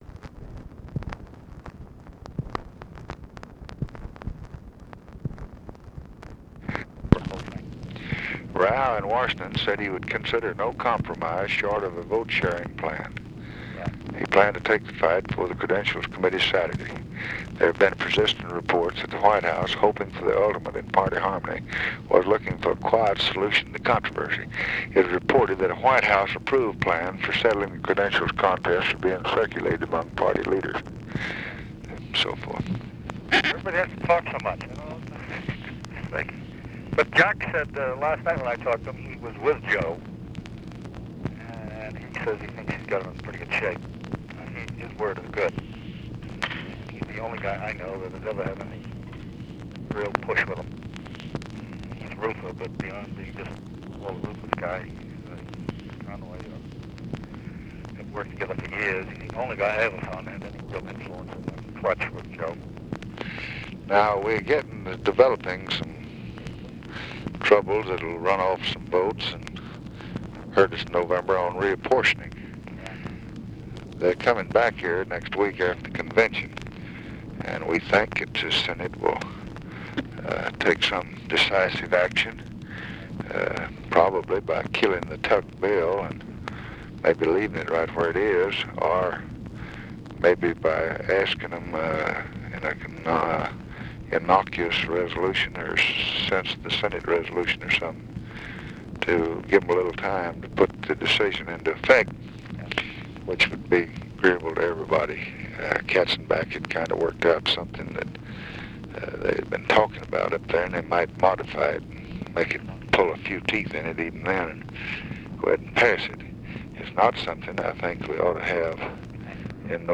Conversation with KEN O'DONNELL, August 21, 1964
Secret White House Tapes